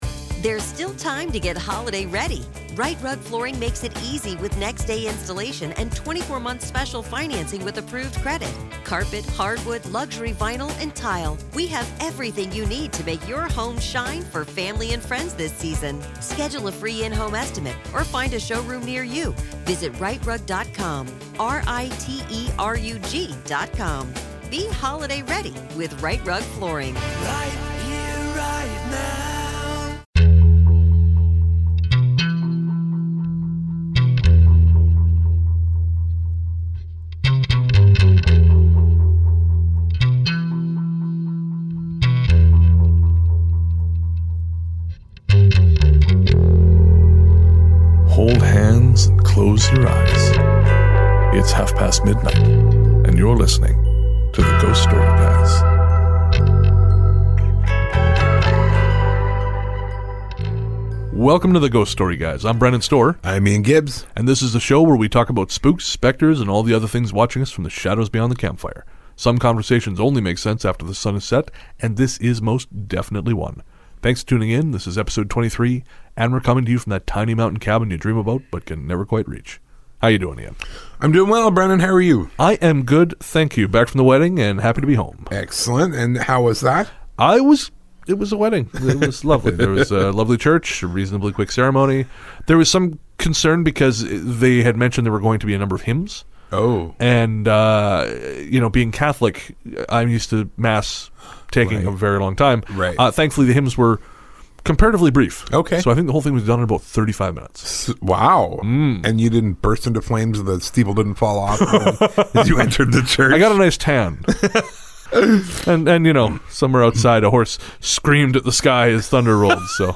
During the recording of the LA episode we picked up a strange noise on one of the mics and we've decided to release that to you, our listeners, to see what you think and get your feedback. You'll find that clip near the end.